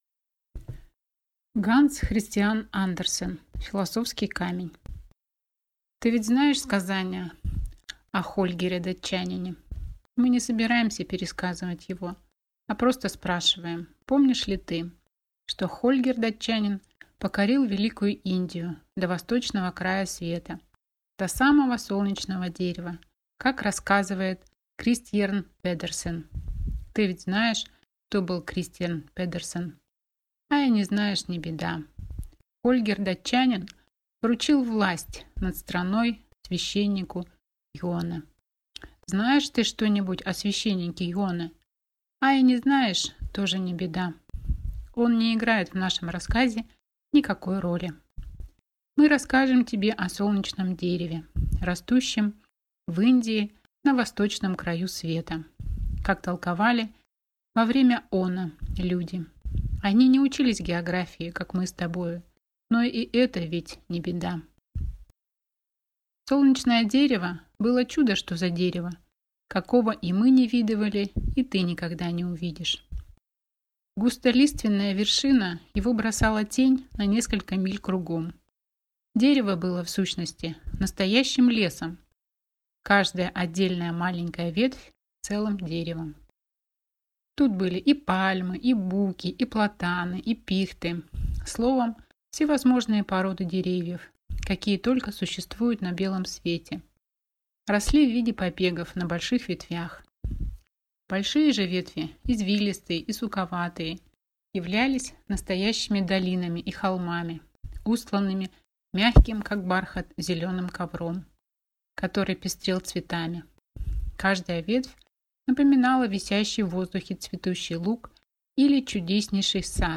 Аудиокнига Философский камень | Библиотека аудиокниг